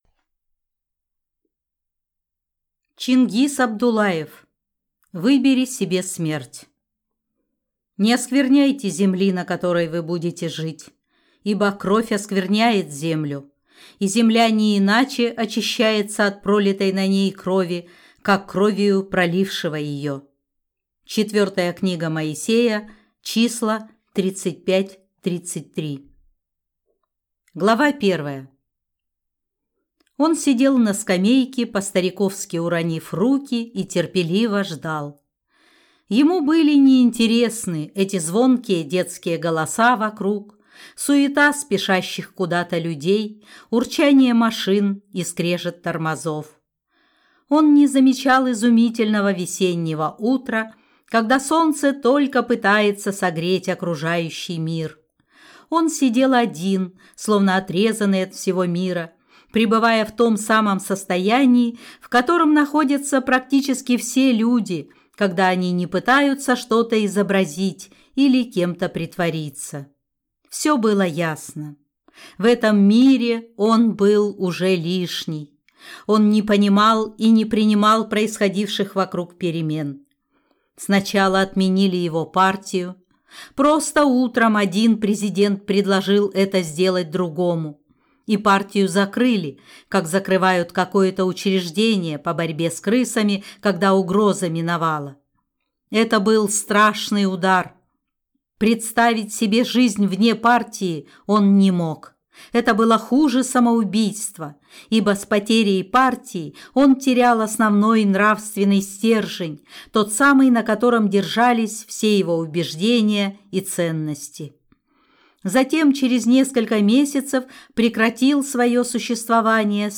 Аудиокнига Выбери себе смерть | Библиотека аудиокниг